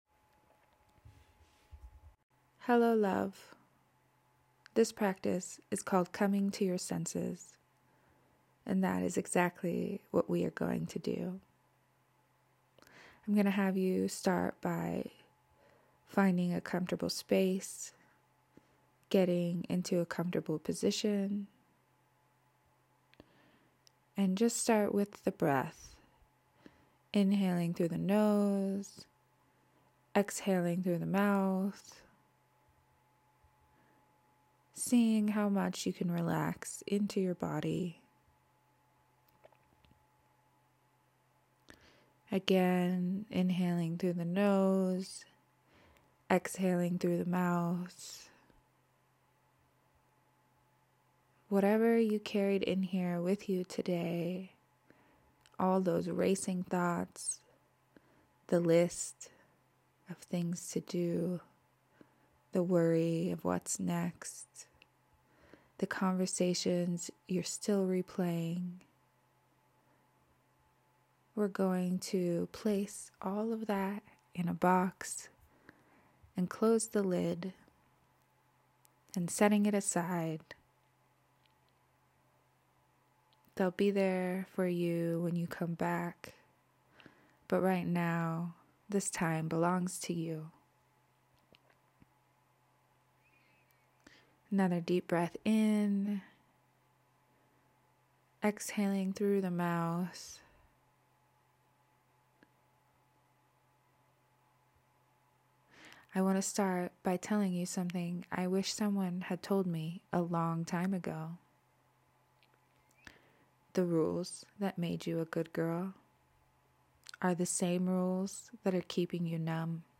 Sign up to received my free guided meditation INSIDE THIS GUIDED AUDIO YOU WILL: 01 . Regulate your nervous system in real time 02.